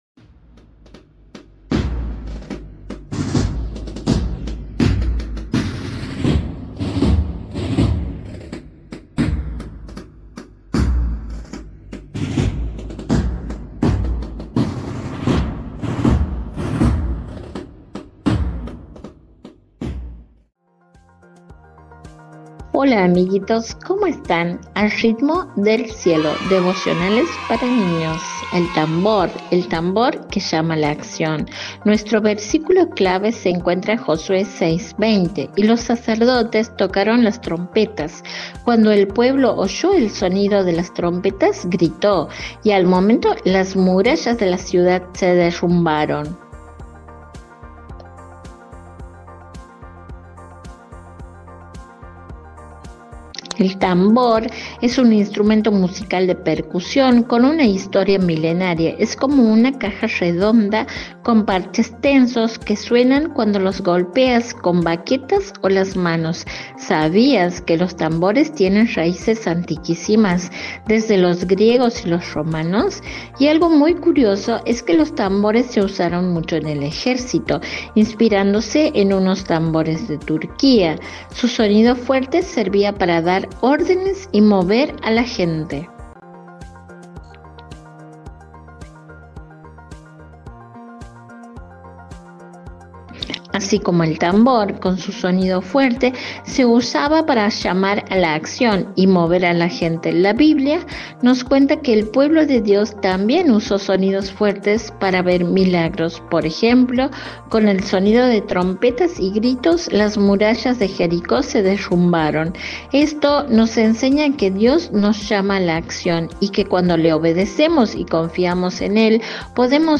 – Devocionales para Niños